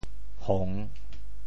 潮州 hong3 文 对应普通话: hòng 吵闹，搅扰：起～（故意吵闹扰乱，亦指开玩笑） | ～场（指观众喝倒彩） | ～抢 | 一～而起。
hong3.mp3